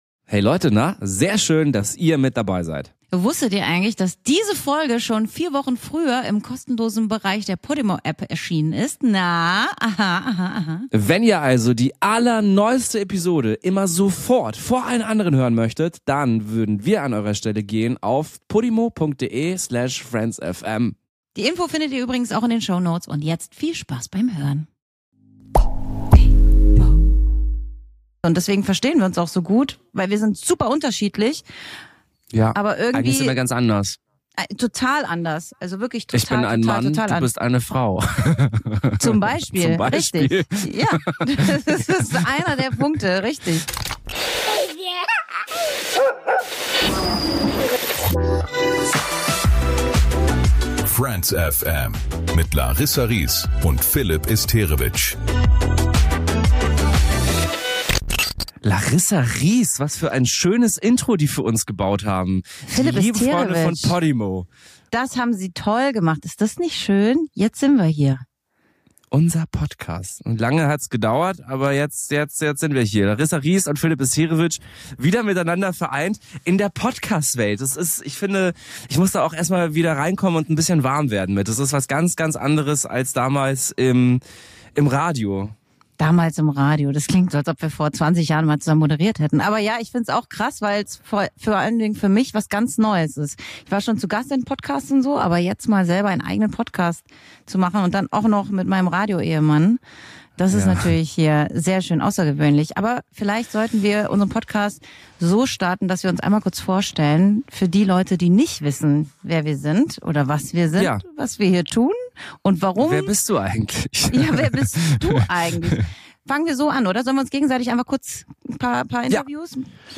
Es wird laut, es wird ehrlich und es wird genau so, wie ein Gespräch unter besten Freunden sein sollte.